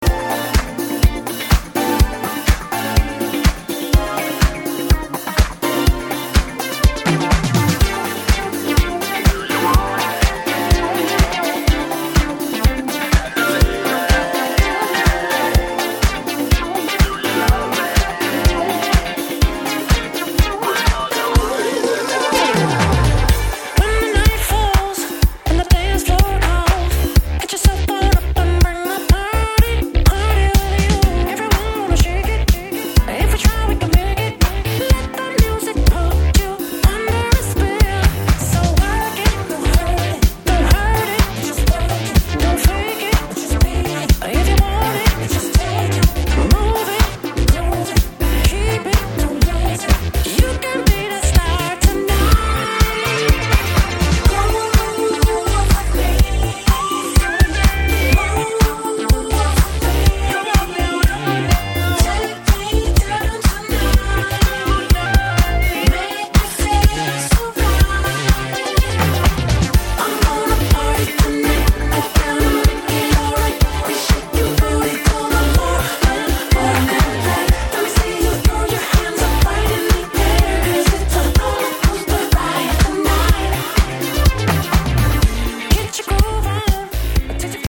This is also the most dynamic boogie disco!